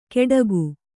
♪ keḍagu